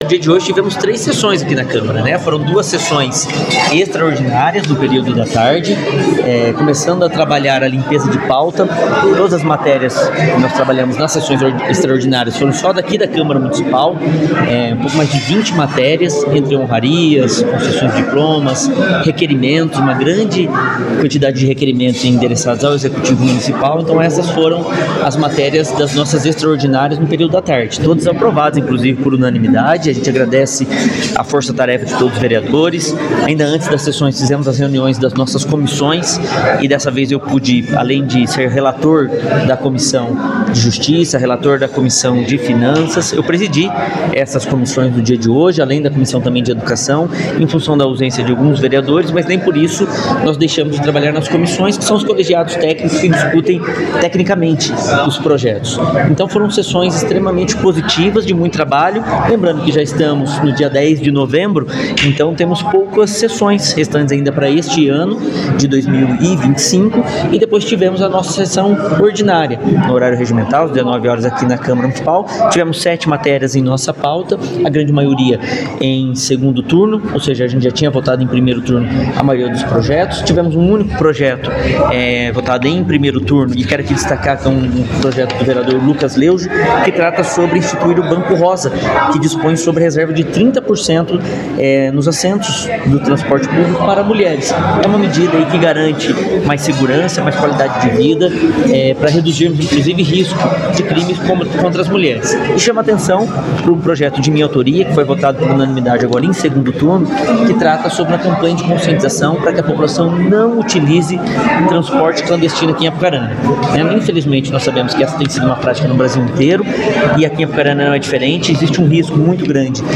• Acompanhe as entrevistas realizadas com os vereadores após a sessão que teve a participação on-line de Odarlone Orente e as faltas justificadas, de Tiago Cordeiro e Guilherme Livoti.